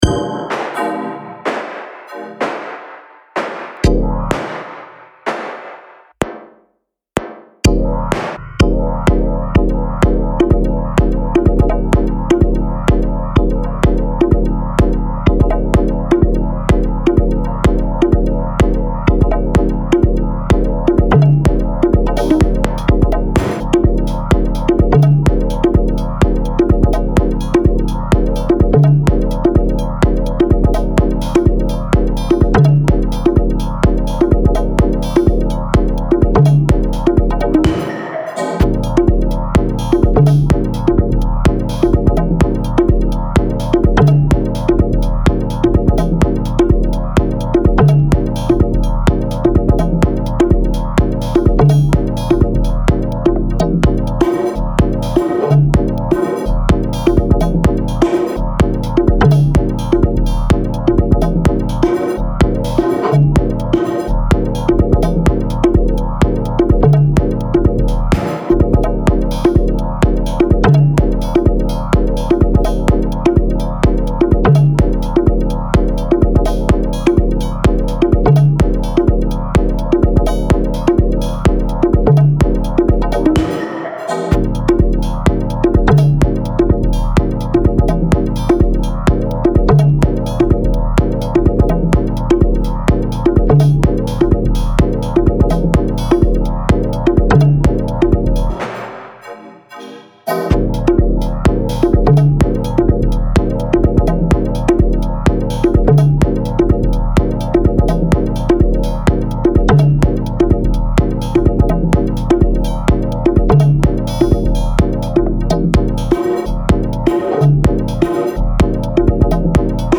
Техно болото (вертим мрачные, колючие, грязные тембры)